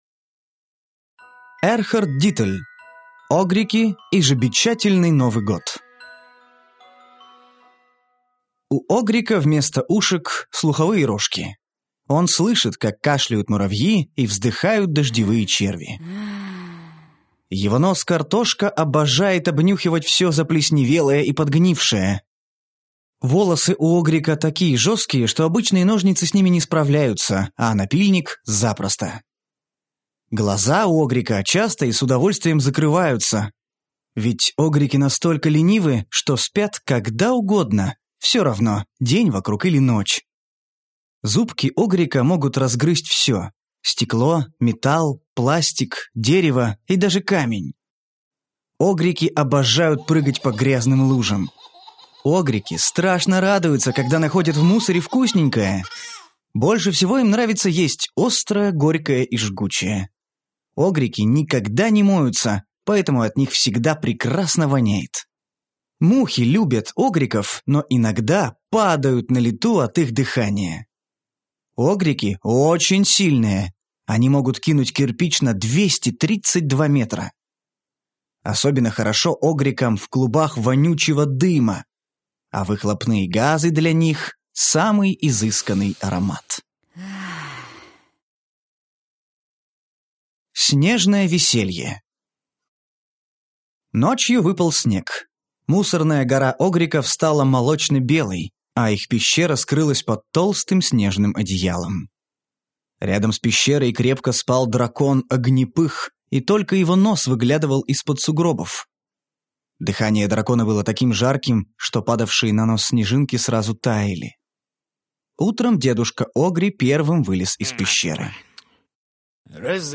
Аудиокнига Огрики и жабечательный Новый год | Библиотека аудиокниг